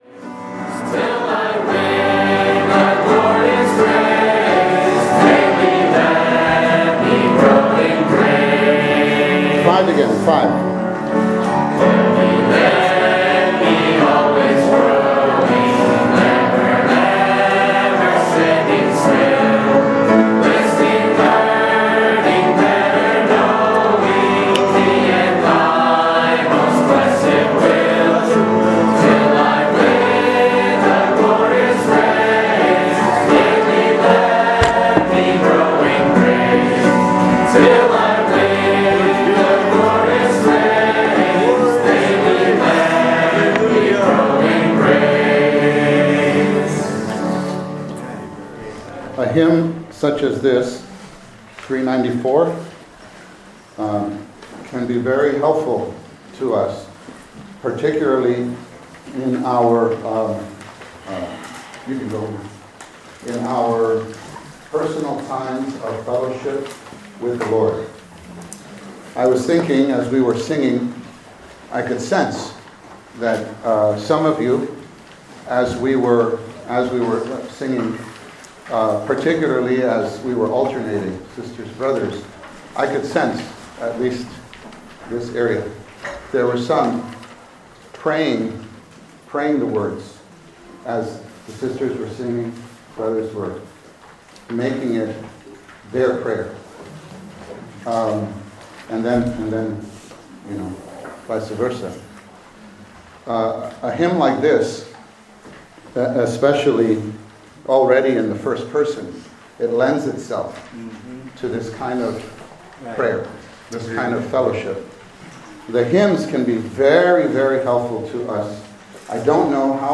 Advancing in Our Experience of Consecration, to Reach the Topmost Consecration, the Consecration to the God of the House of God (El-Bethel) This conference was held at at KPCC (Camp Penuel)